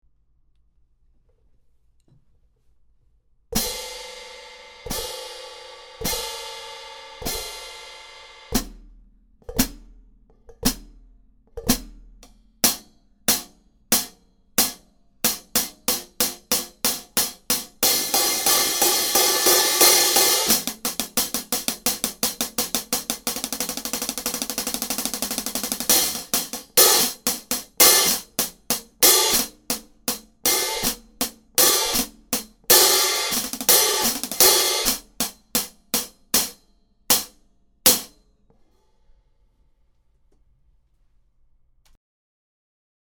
Overtones are reduced and the tone is mellowed making this series popular for recording and for live usage. Less lathing, more raw (unlathed) areas means less resonance and wash, providing a quicker decay than the Classic series.
16″ Studio hi-hat cymbals: Approx 2995 Grams Combined. Top Hat 13758 Grams. Bottom Hat 1575 Grams
HB-16_-Studio-Hats.mp3